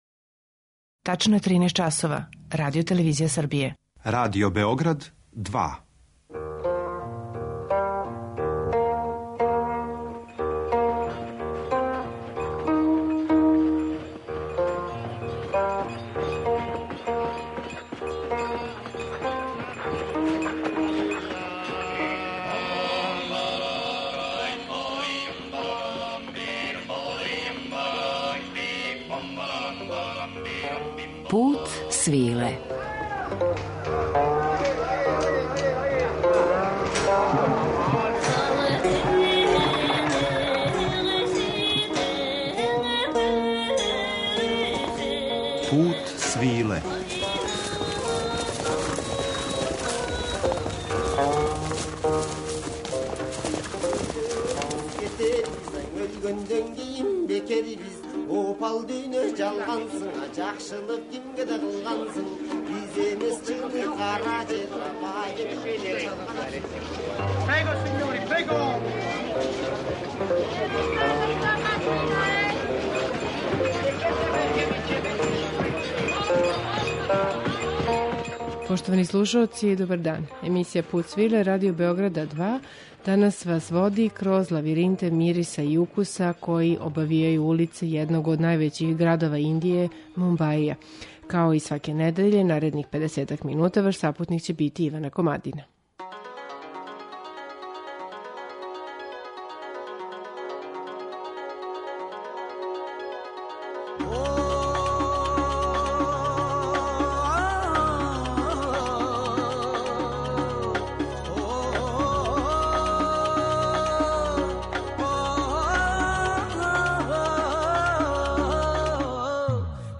Музичку пратњу пружиће нам уметници који су заштитни знак не само овог града већ и целе Индије - музичари који су део велике империје Боливуда, филмске индустрије која још увек чува старо име Мумбаја - Бомбај.